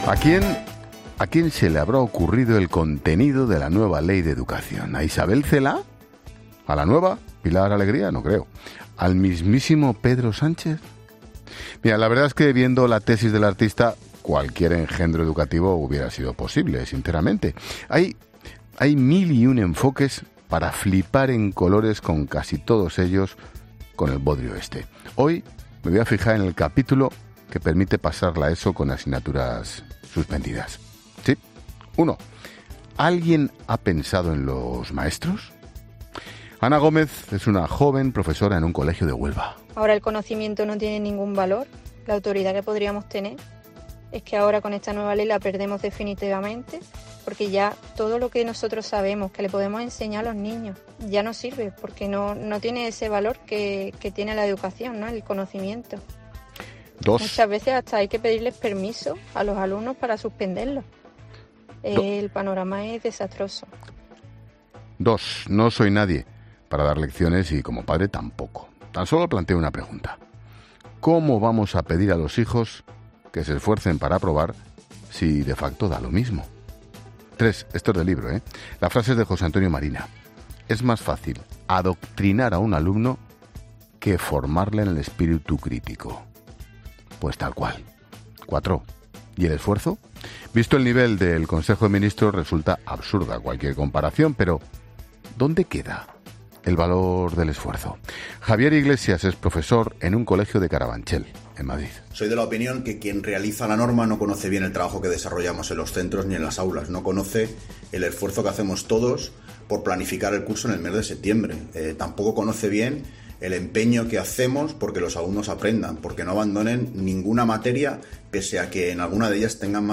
AUDIO: El director de 'La Linterna' critica en su monólogo de este miércoles la nueva Ley de Educación del Gobierno